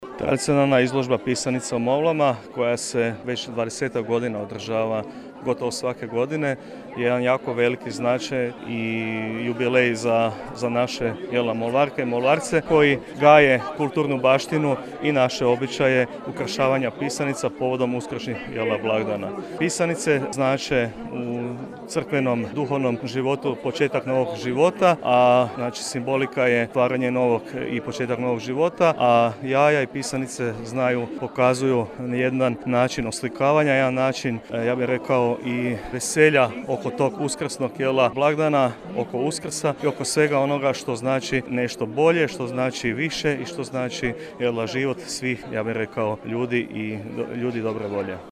a onda je svoju osvrt dao i Općinski načelnik Molva, Zdravko Ivančan